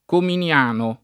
[ komin L# no ]